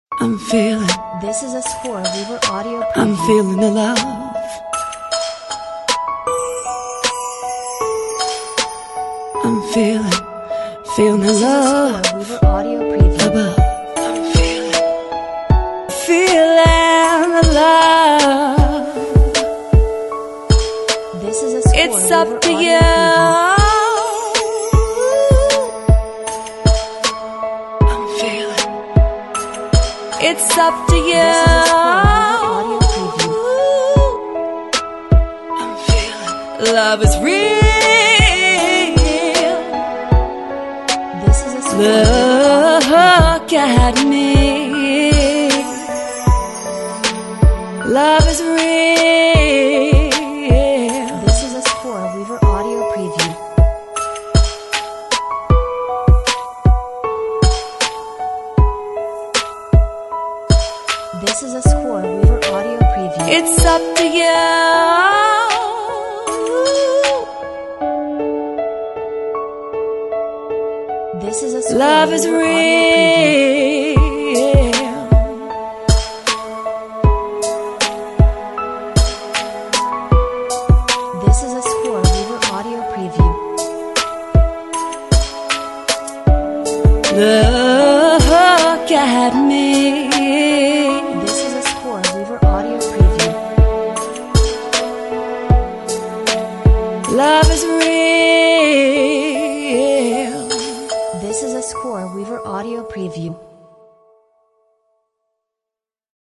Crystal clear production and a wide variety of uses.